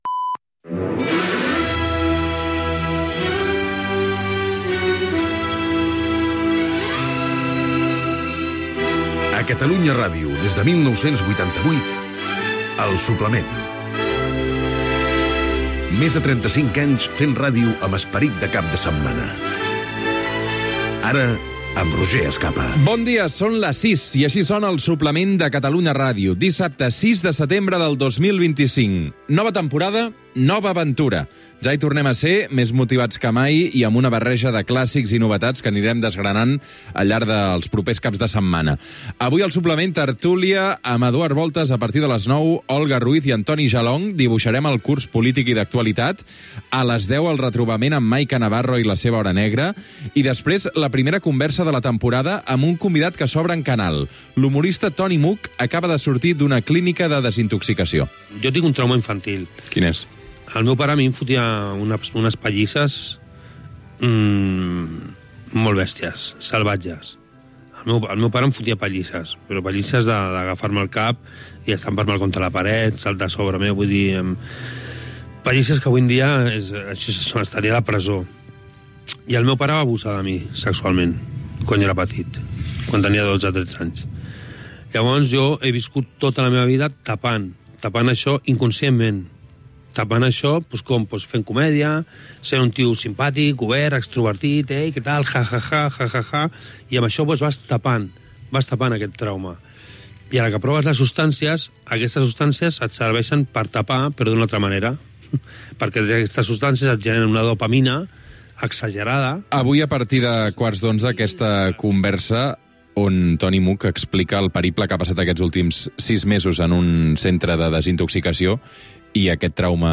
Careta, amb esment que el programa s'emet des de 1988. Hora, inici de la temporada 2025-2026.
Entreteniment
FM